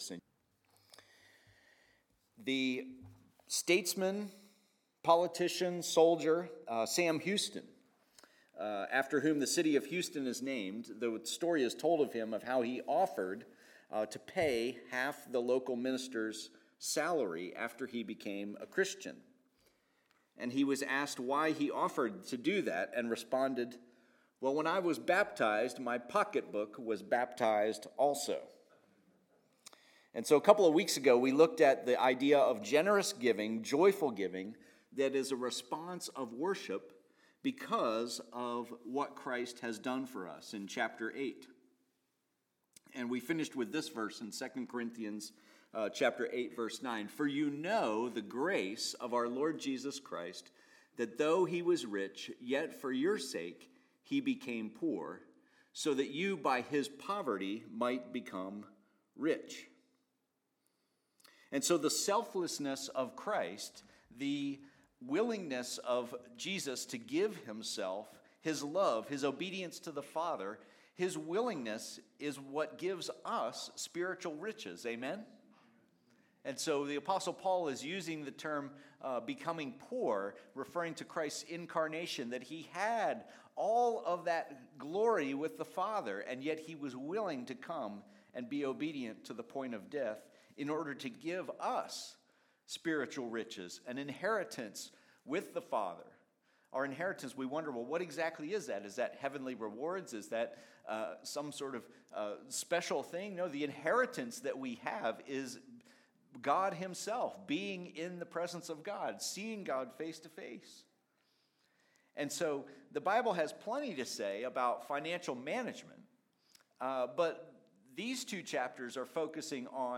Message from 2 Corinthians 9 on grace as the motivation for giving.